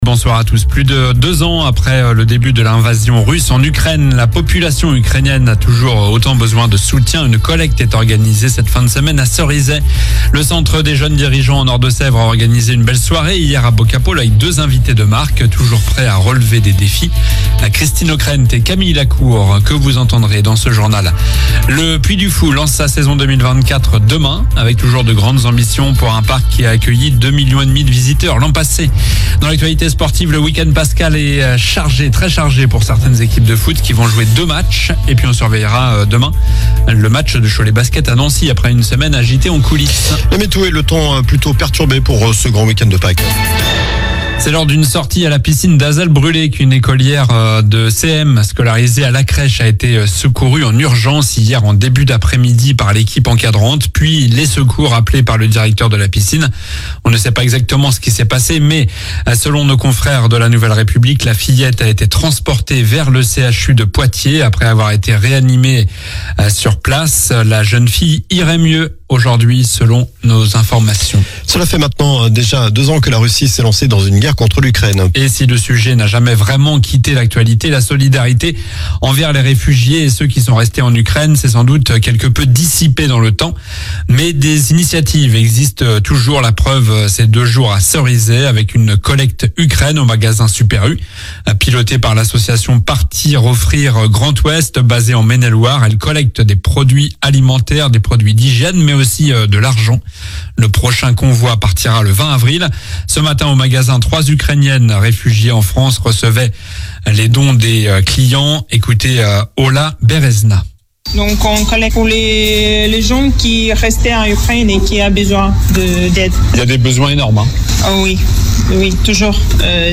Journal du vendredi 29 mars (soir)